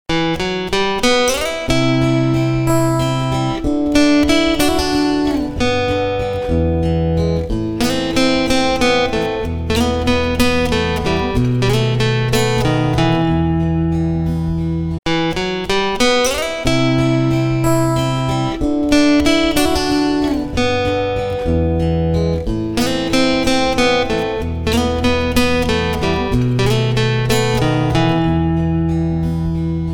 • Качество: 320, Stereo
душевные
красивая мелодия
русский рок
трогательные
(вступление, гитара)